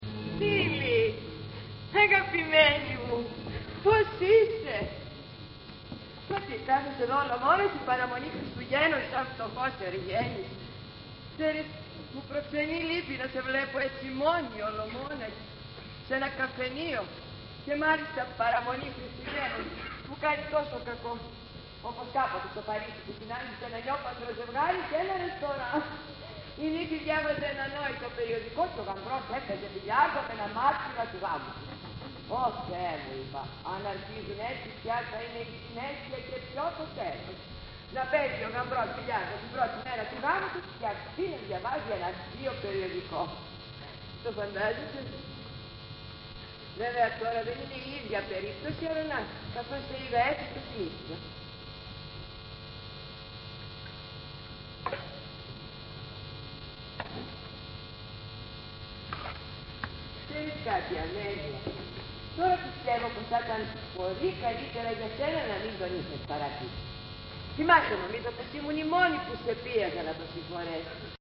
Ηχογράφηση Παράστασης
Αποσπάσματα από την παράσταση